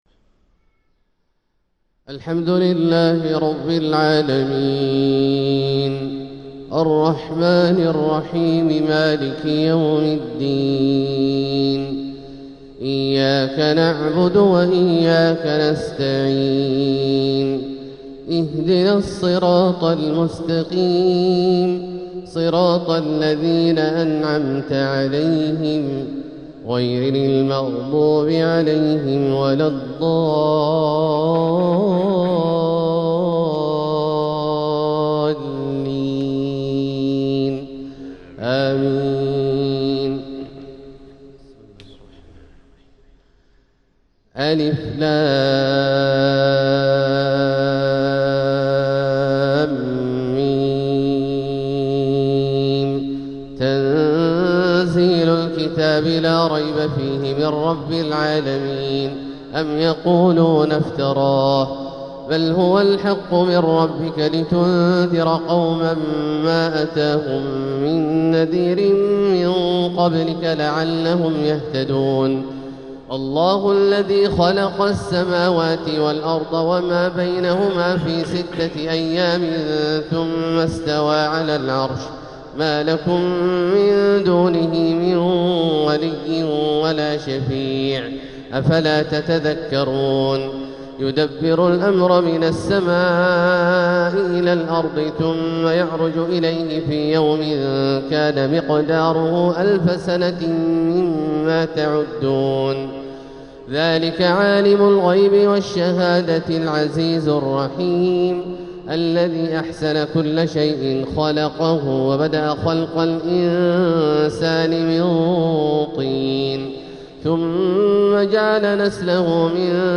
تلاوة لسورتي السجدة و الإنسان | فجر الجمعة 7-6-1447هـ > ١٤٤٧هـ > الفروض - تلاوات عبدالله الجهني